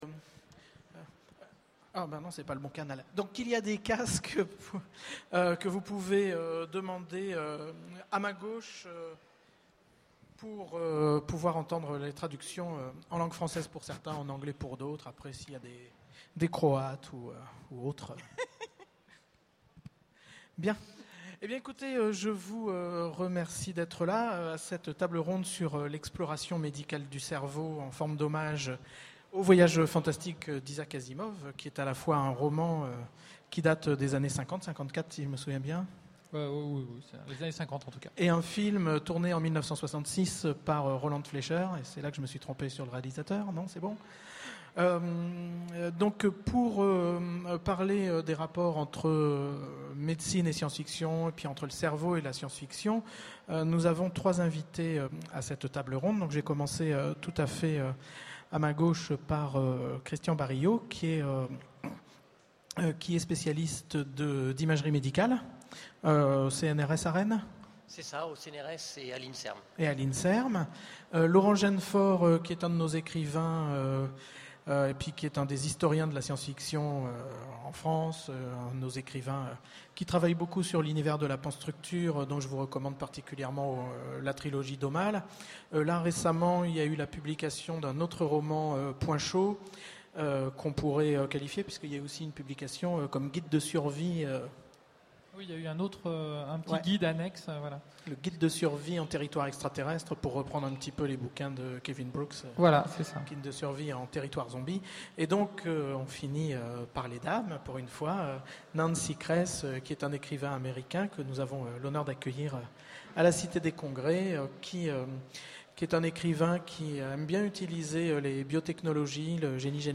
Utopiales 12 : Conférence L’exploration médicale du cerveau